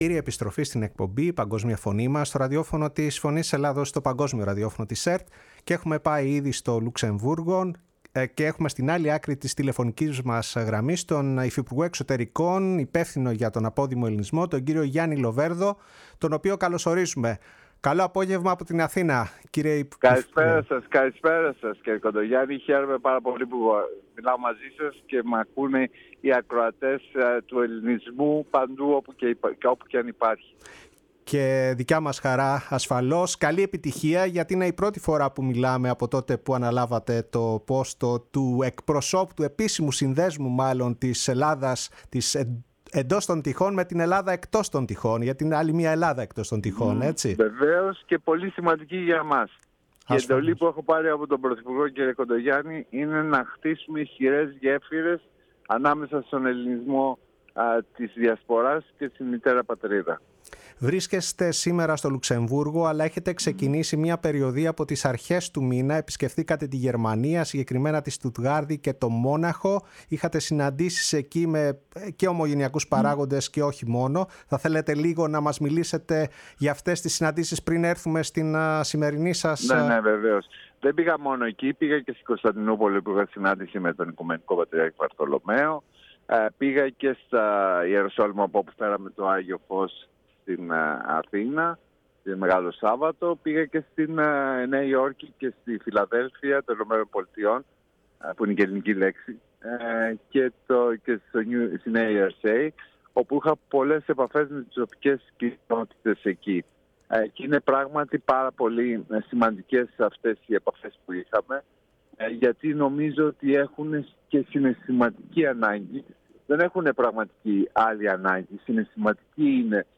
Η ΦΩΝΗ ΤΗΣ ΕΛΛΑΔΑΣ Η Παγκοσμια Φωνη μας ΣΥΝΕΝΤΕΥΞΕΙΣ Συνεντεύξεις Γερμανια Γιαννης Λοβερδος ελληνικη γλωσσα ΛΟΥΞΕΜΒΟΥΡΓΟ Ομογενεια υφυπουργος Εξωτερικων